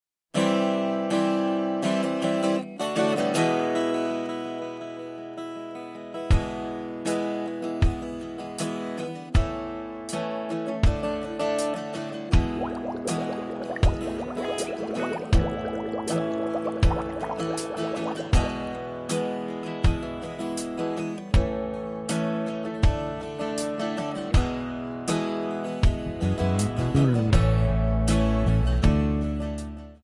Backing track files: 1980s (763)
Buy With Backing Vocals.